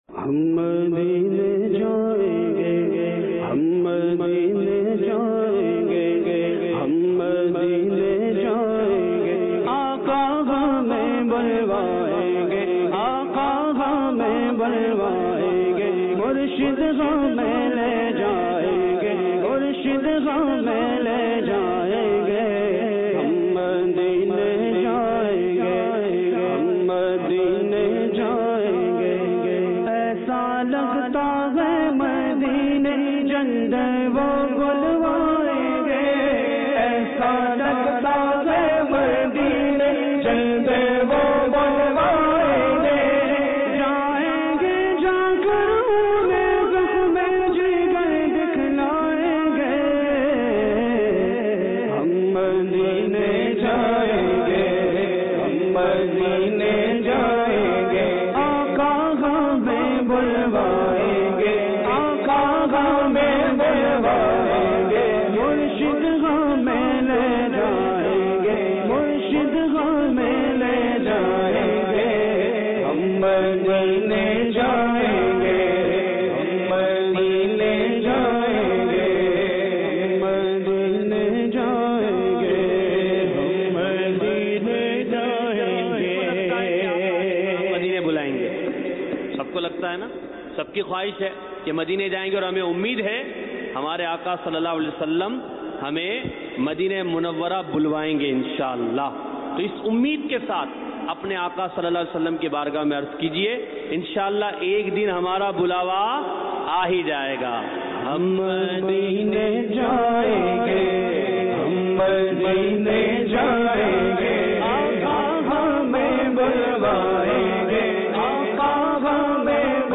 اجتماعِ ذکرونعت میں پڑھا جانے والا
خوبصورت آواز میں